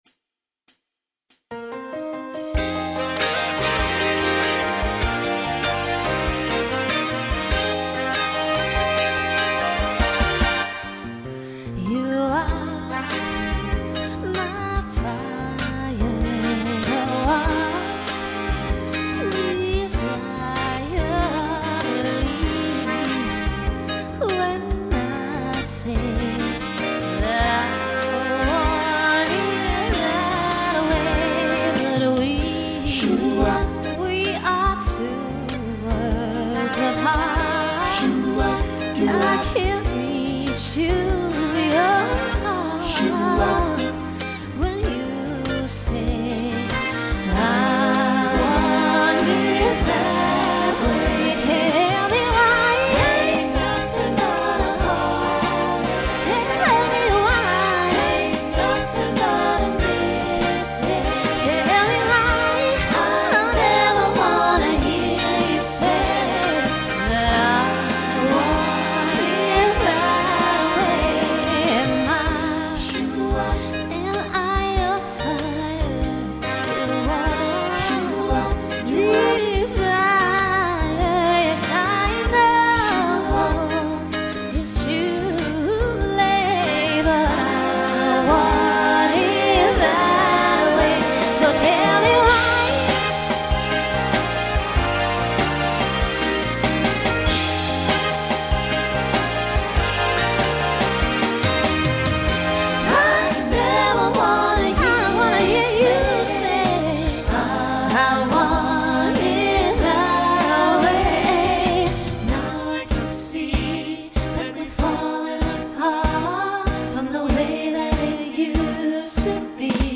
Voicing SATB
Swing/Jazz
Mid-tempo